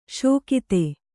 ♪ śokite